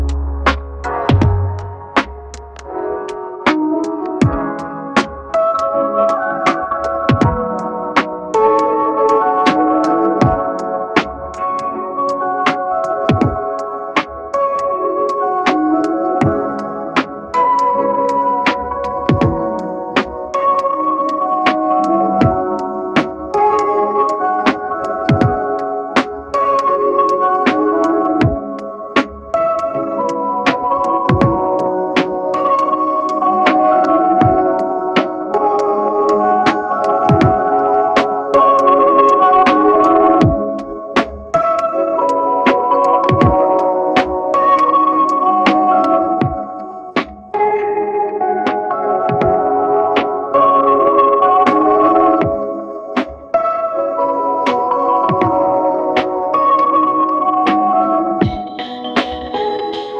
infinifi - infinifi plays gentle lofi music in the background indefinitely